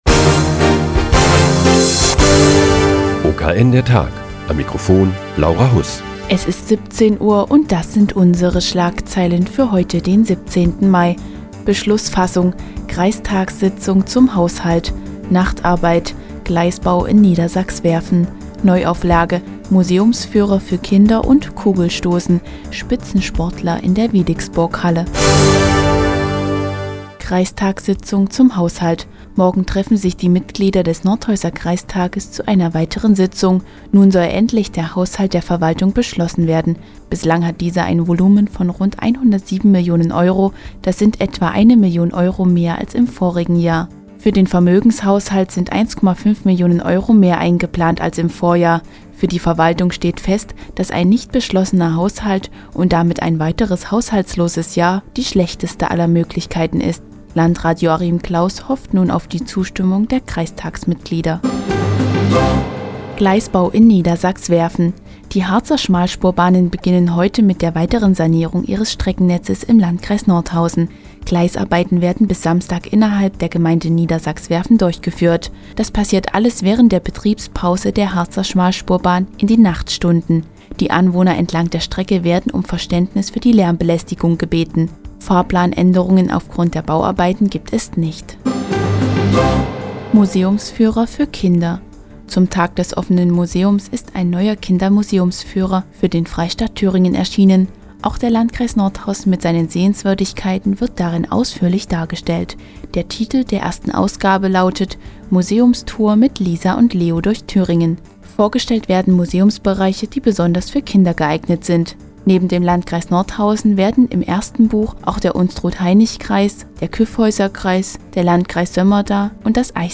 Die tägliche Nachrichtensendung des OKN ist nun auch in der nnz zu hören. Heute geht es um die morgige Sitzung des Nordhäuser Kreistages und den neuen Kinder-Museumsführer für den Freistaat Thüringen.